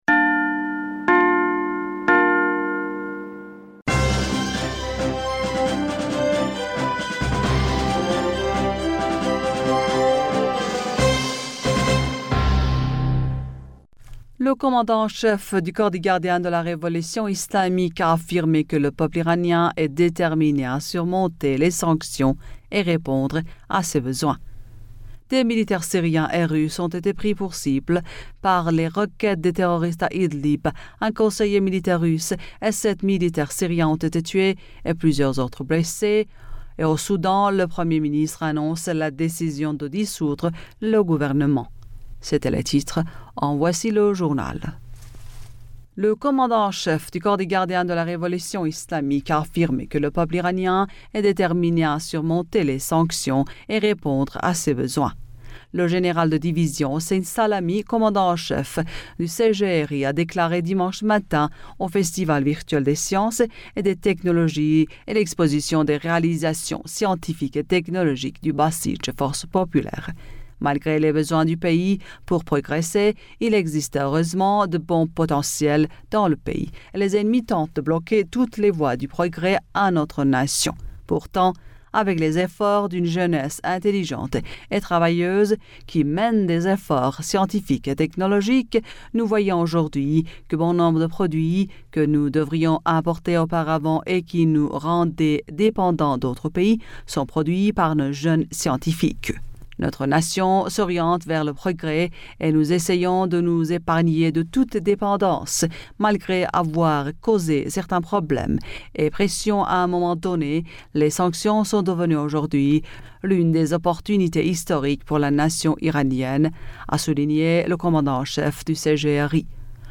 Bulletin d'informationd u 08 Février 2021